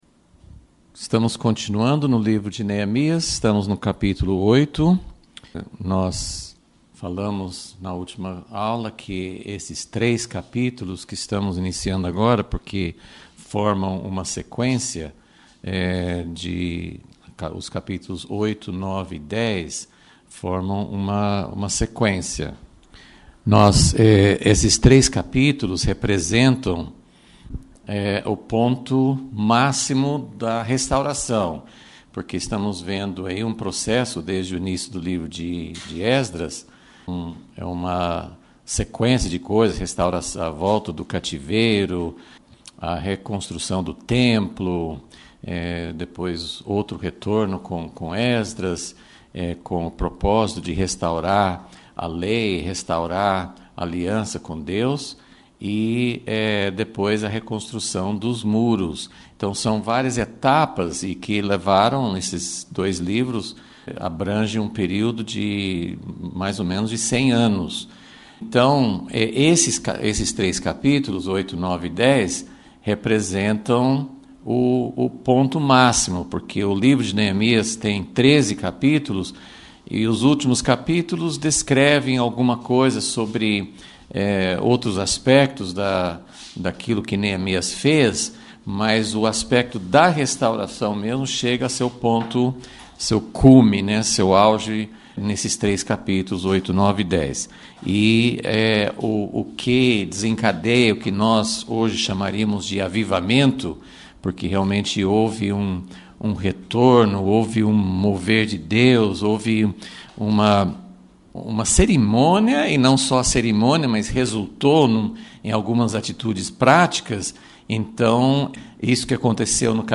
Aula 20 – Vol.36 – Avivamento da Palavra e pela Palavra